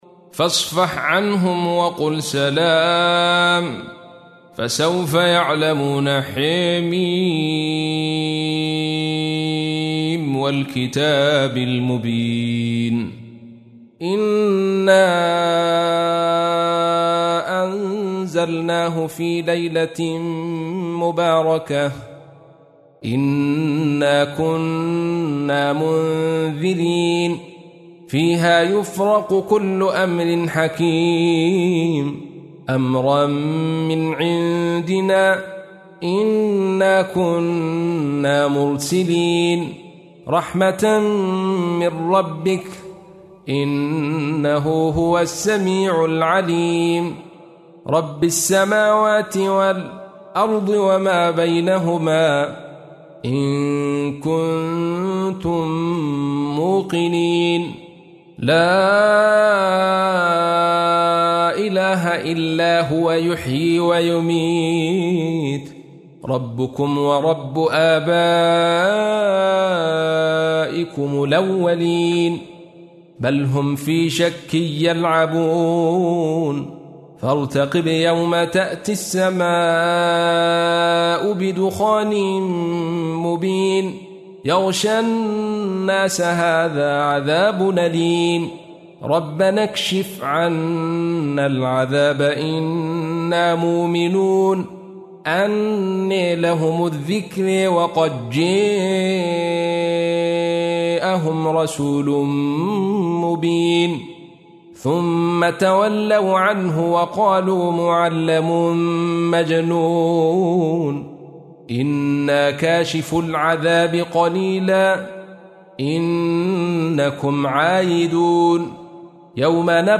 تحميل : 44. سورة الدخان / القارئ عبد الرشيد صوفي / القرآن الكريم / موقع يا حسين